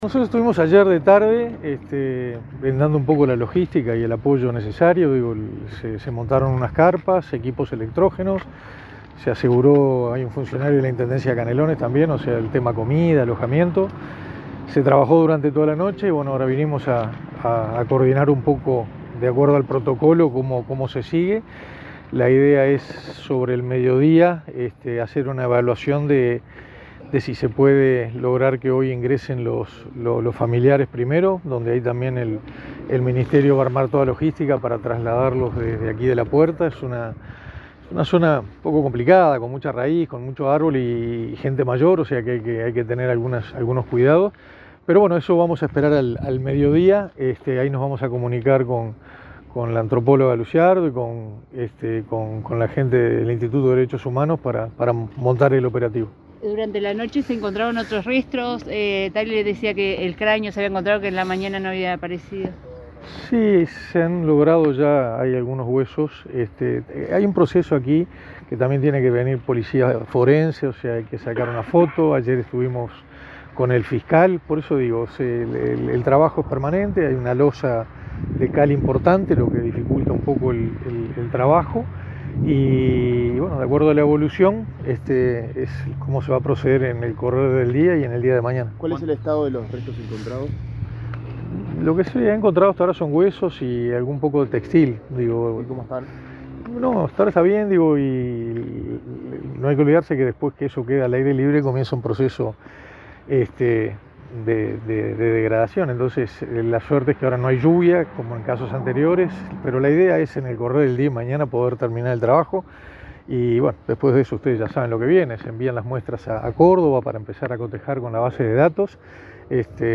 Declaraciones del ministro de Defensa Nacional, Armando Castaingdebat
Declaraciones del ministro de Defensa Nacional, Armando Castaingdebat 31/07/2024 Compartir Facebook X Copiar enlace WhatsApp LinkedIn El ministro de Defensa Nacional, Armando Castaingdebat, dialogó con la prensa, durante su visita al batallón n°.14 de Toledo, en Canelones, donde se encontraron restos óseos.